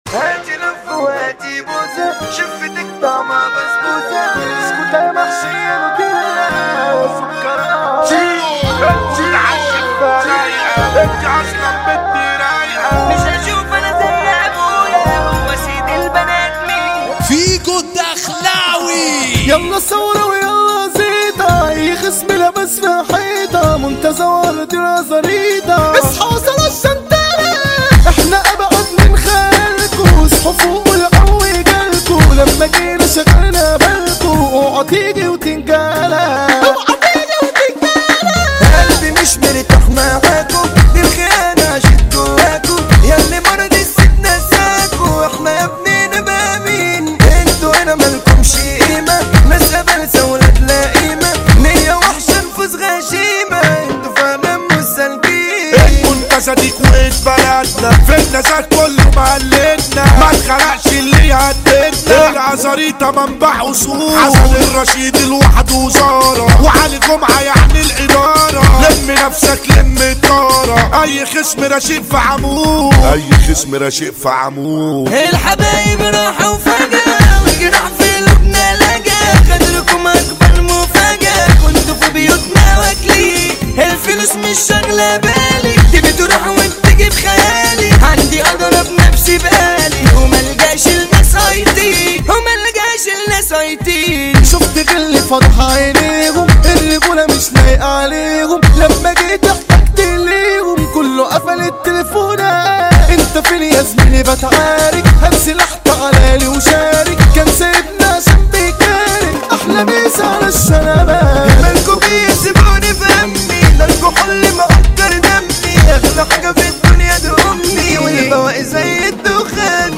مهرجانات جديدة